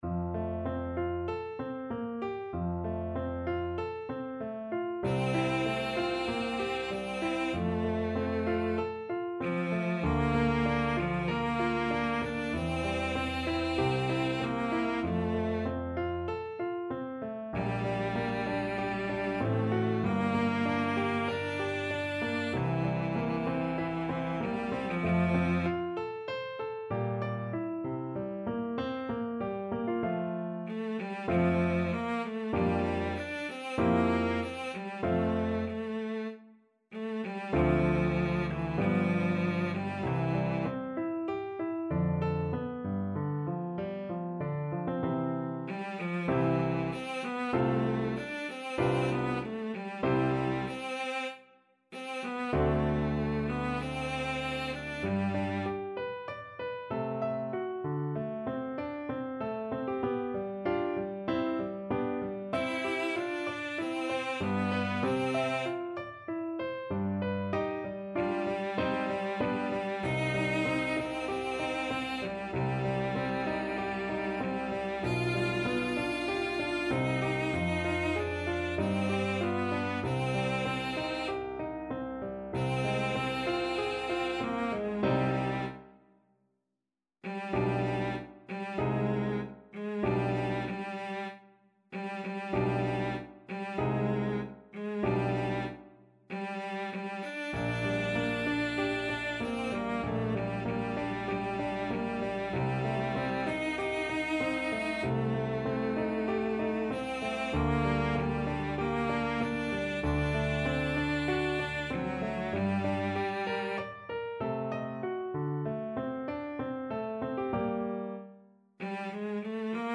Classical Mozart, Wolfgang Amadeus Abendempfindung an Laura, K.523 Cello version
Cello
F major (Sounding Pitch) (View more F major Music for Cello )
~ = 96 Andante
4/4 (View more 4/4 Music)
Classical (View more Classical Cello Music)